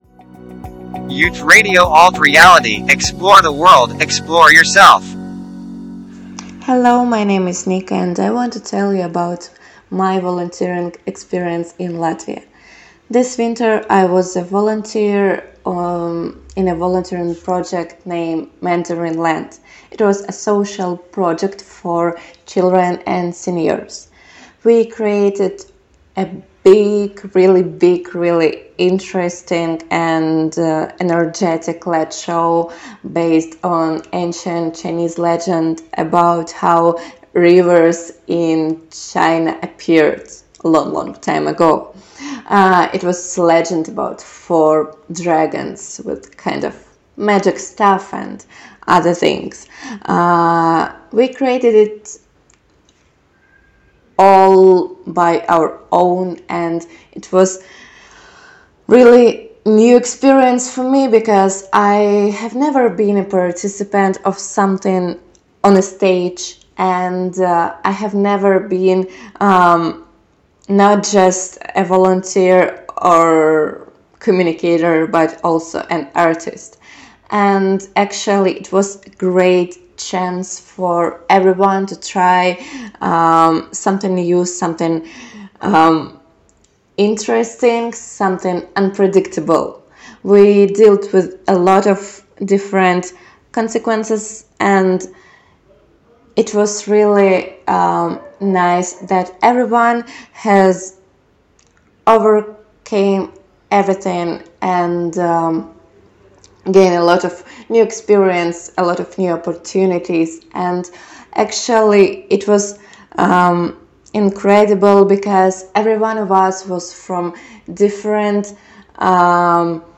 Podkasts: brīvprātīgais darbs. Projekts “Mandarīnu Zeme 2022”